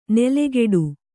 ♪ nelegeḍu